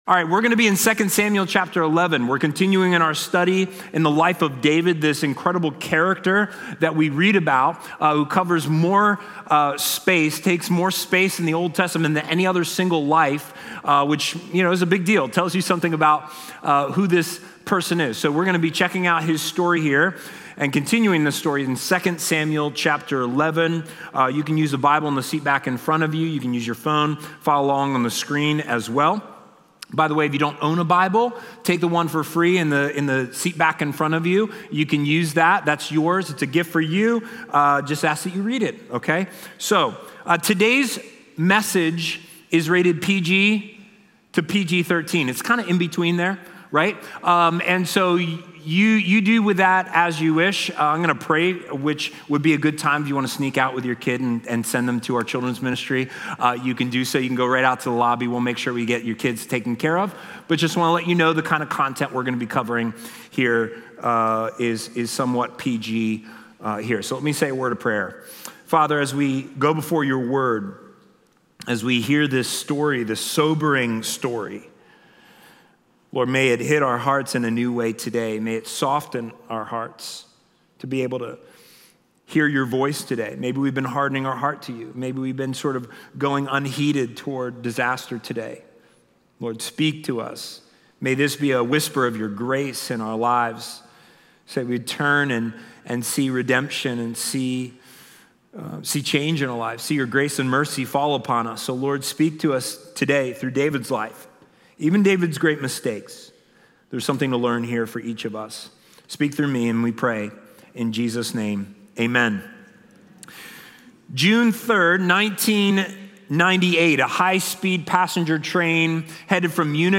Communion is part of this service.